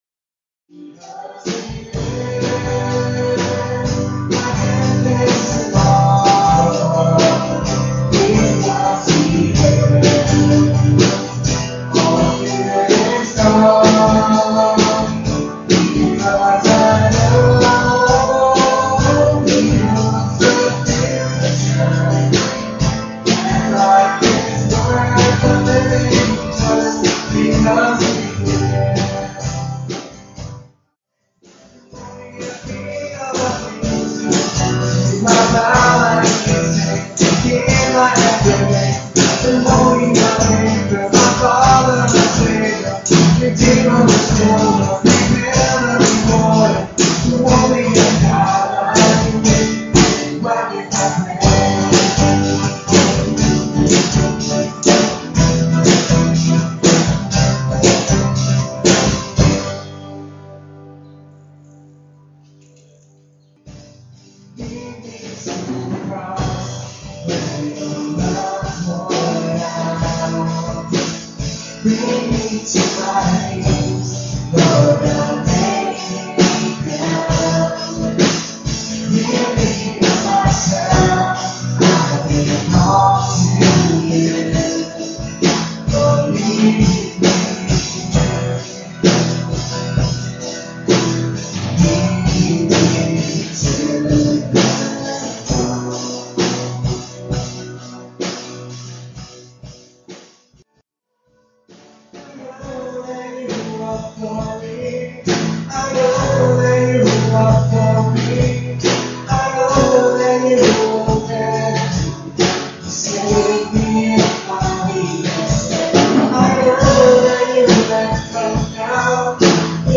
at Ewa Beach Baptist Church